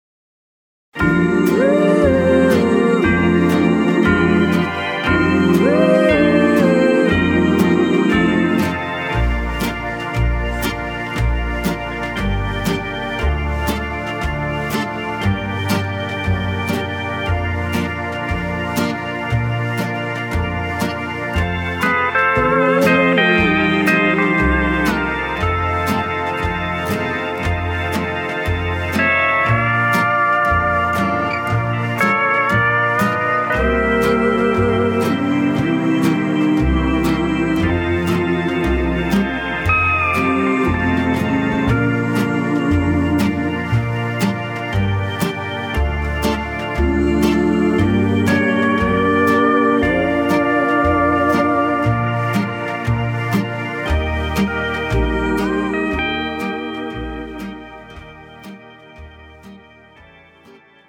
MR 고음질 반주
고품질 MR 다운로드.